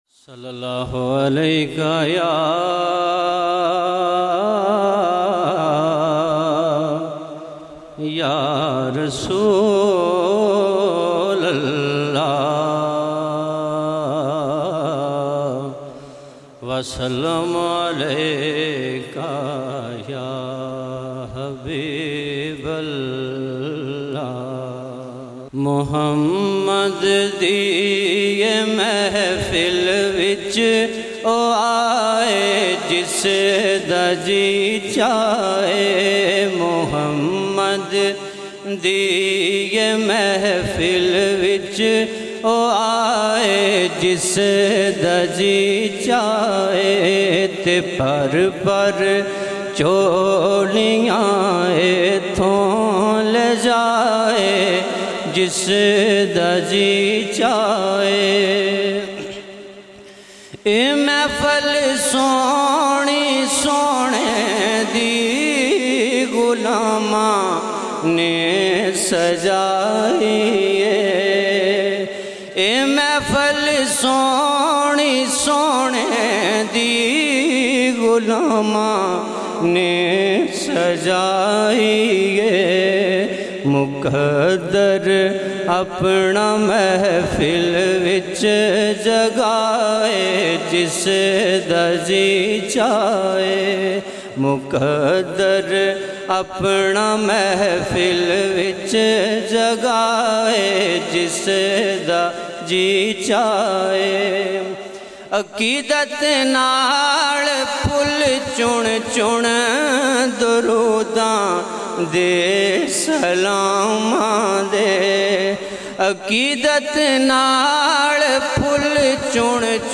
آڈیو نعتیں